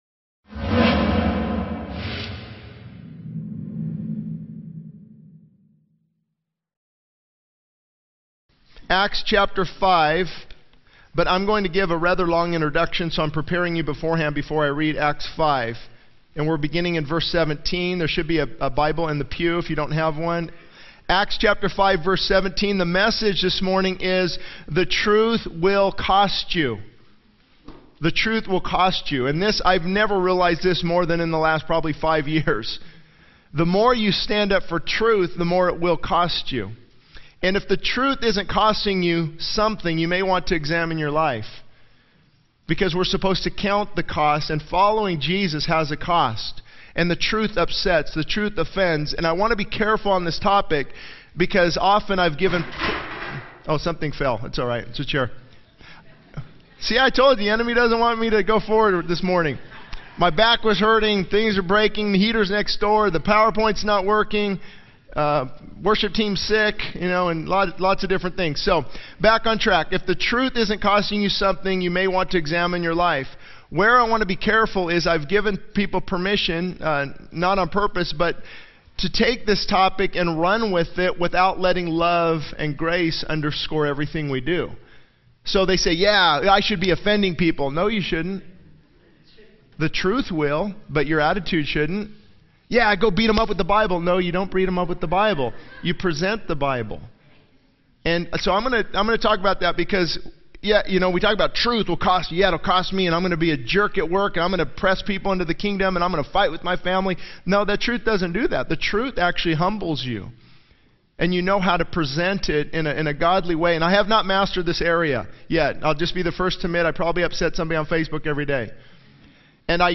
This sermon emphasizes the cost of standing up for truth, highlighting the need to examine one's life if the truth isn't costing anything. It discusses the offense of truth, the importance of presenting truth with love and grace, and the need to surrender our will to God's will. The message focuses on Acts 5, where the disciples faced imprisonment for preaching the truth but were miraculously freed by an angel to continue speaking the words of life despite opposition.